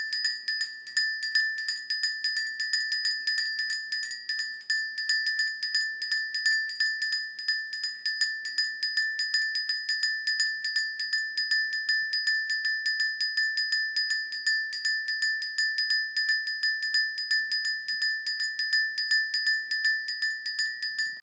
Dekorácia srdca so zvončekom 40cm